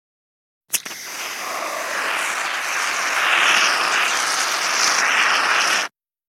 Звуки монтажной пены
Шипение аэрозоля с монтажной пеной